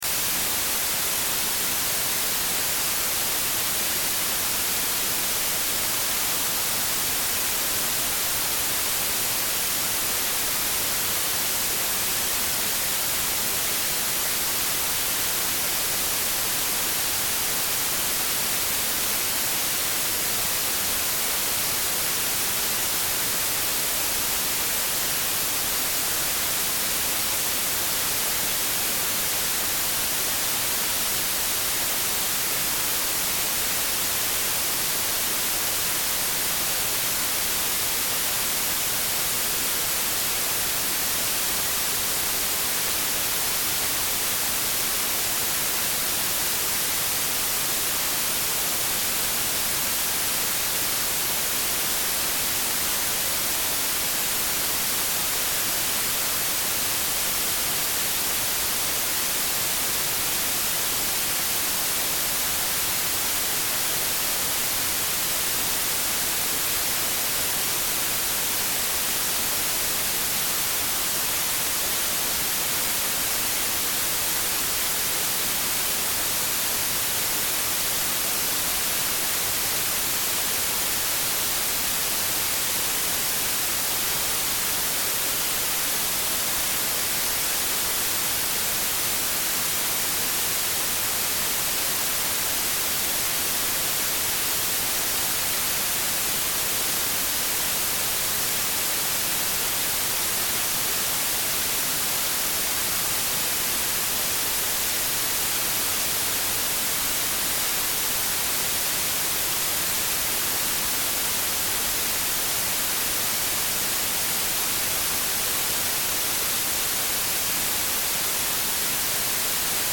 本来、ＴＲＴは耳掛け型補聴器に似たＴＣＩ(Tinnitus Control Instrument)装置とよばれるノイズジェネレーターを使用し、耳に優しい快適な雑音を聞くようにしていました。
当院では音源としてＴＣＩが持っていた４種類のノイズ(全く同じではない)+低音ノイズを作成して提供しています。
high_noise_5.mp3